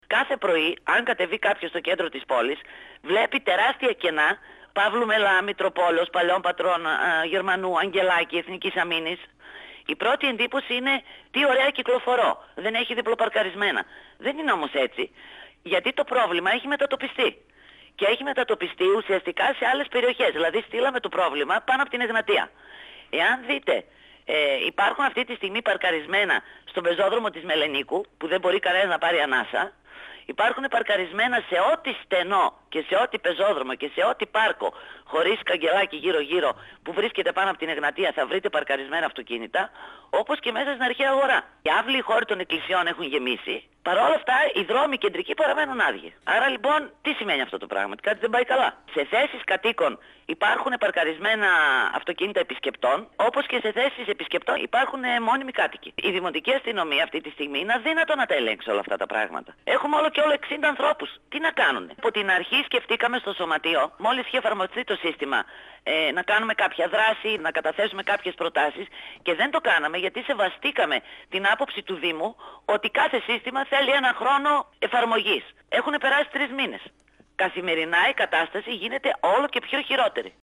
Συνέντευξη